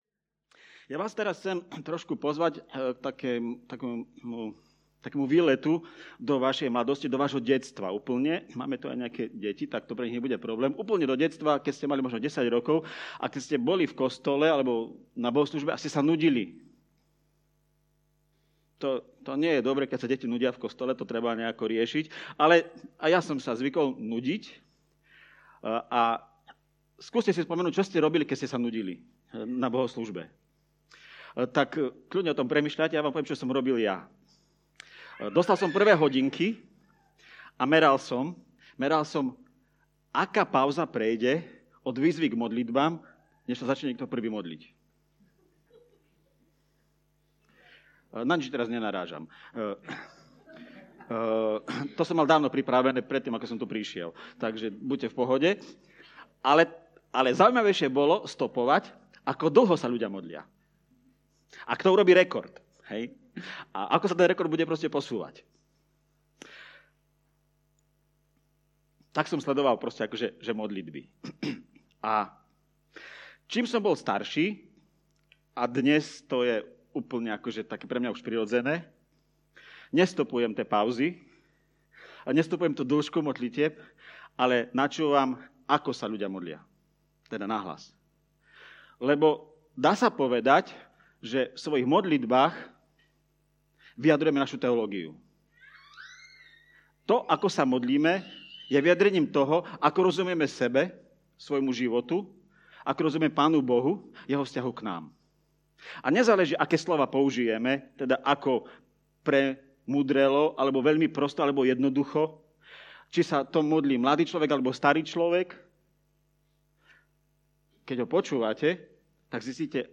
Podcasty Kázne zboru CB Trnava Modliť sa pomalú Modlitbu Pánovu